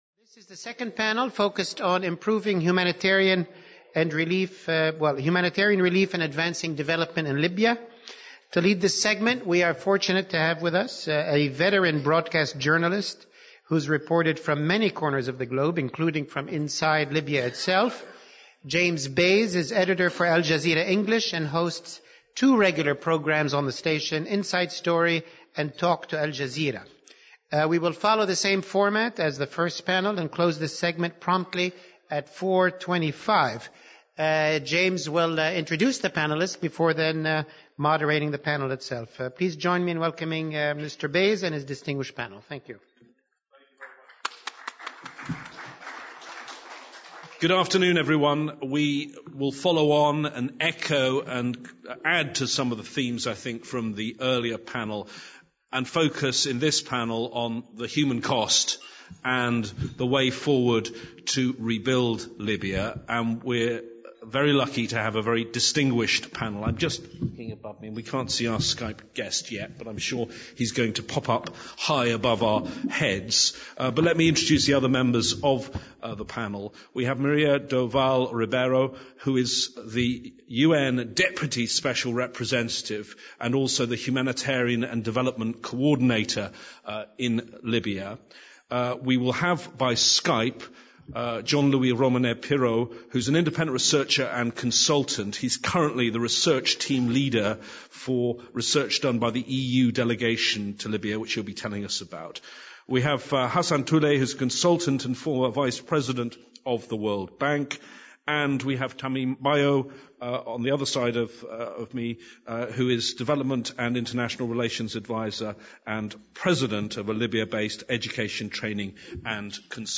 The Middle East Institute (MEI) was pleased to present a two-panel symposium to examine opportunities for the United States and international community to advance Libya's security and mobilize to meet the humanitarian challenges.